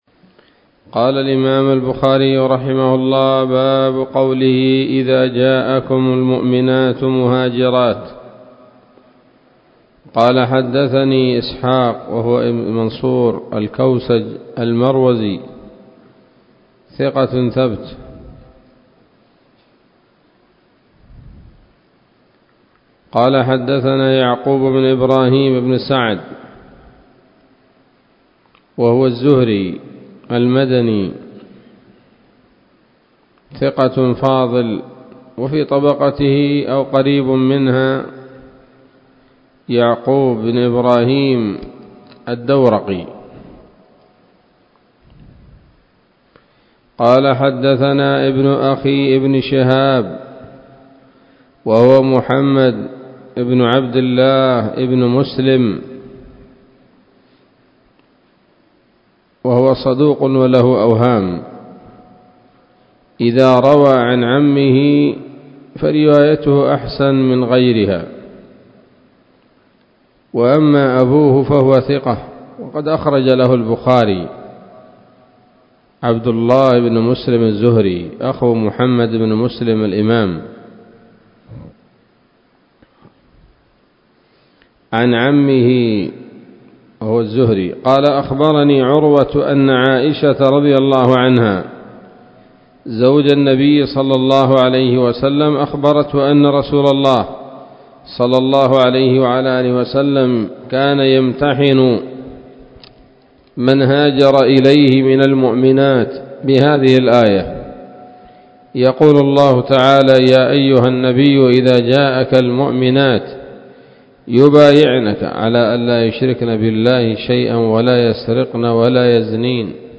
الدرس السابع والخمسون بعد المائتين من كتاب التفسير من صحيح الإمام البخاري